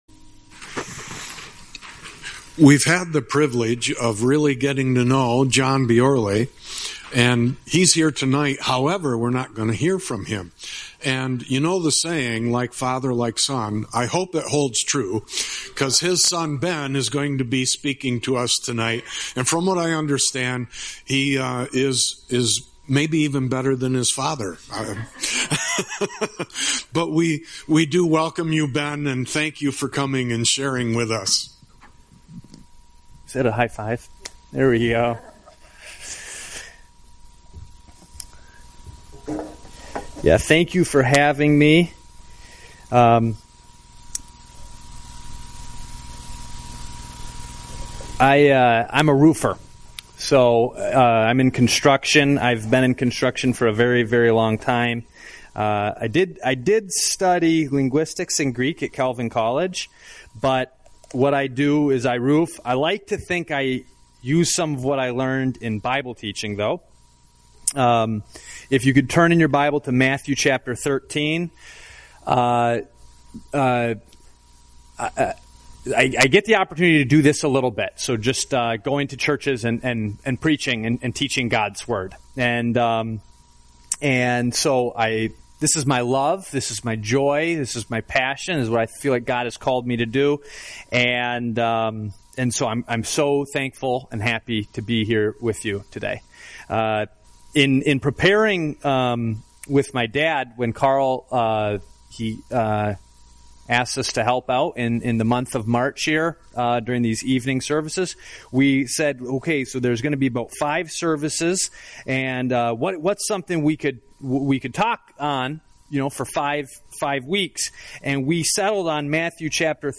Sermon Podcasts | Freeport MI | The Gathering Place - Part 3